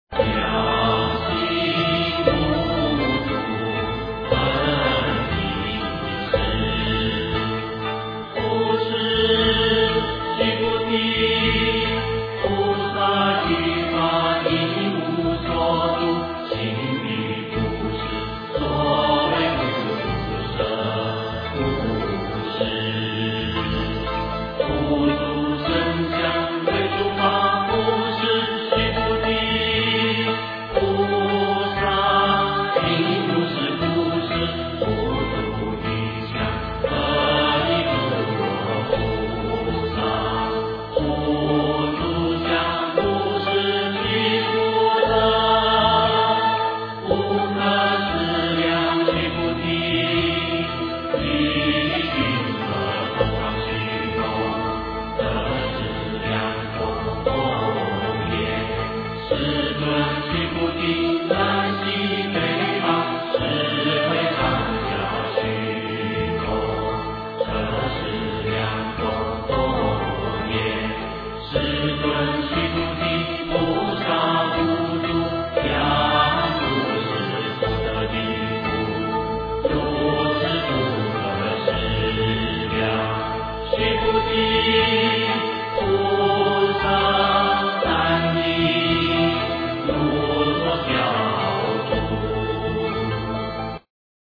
金刚经-妙行无住分第四 诵经 金刚经-妙行无住分第四--未知 点我： 标签: 佛音 诵经 佛教音乐 返回列表 上一篇： 金刚经-大乘正宗分第三 下一篇： 圆觉经 相关文章 佛顶尊胜陀罗尼咒--未知 佛顶尊胜陀罗尼咒--未知...